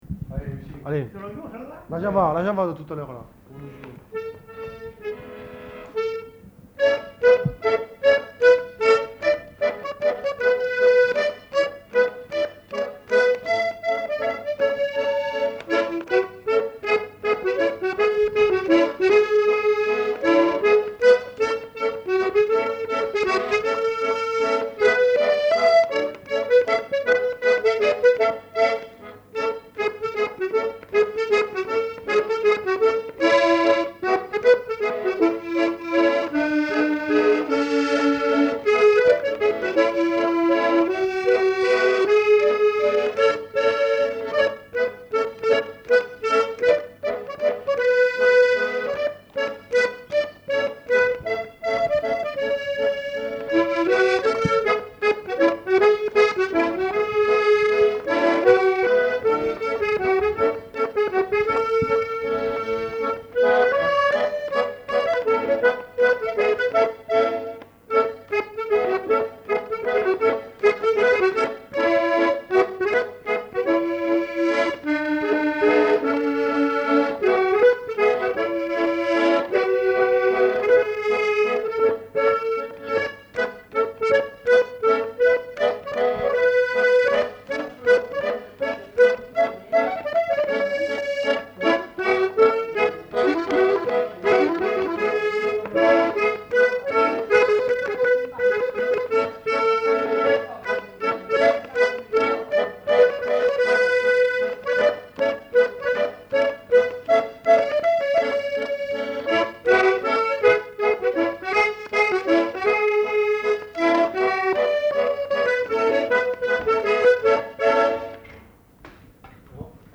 Aire culturelle : Lauragais
Lieu : Gardouch
Genre : morceau instrumental
Instrument de musique : accordéon
Danse : java